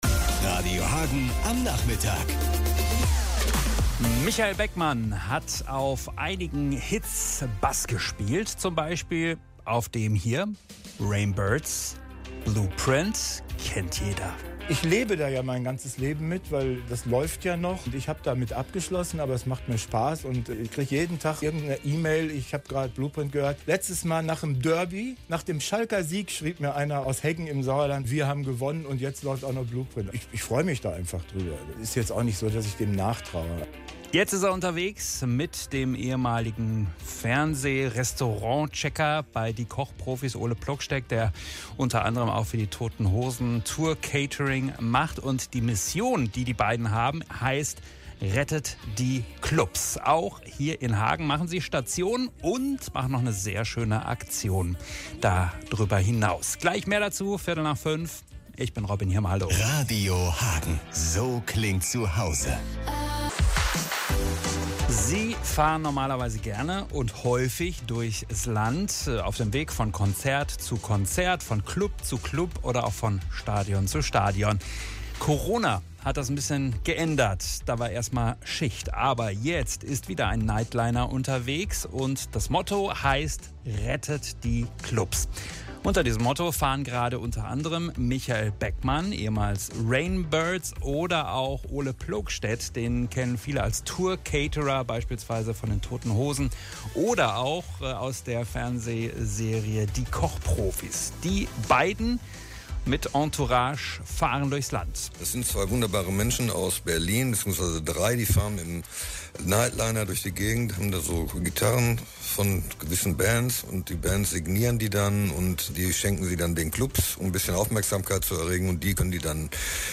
MITSCHNITT AUS DER SENDUNG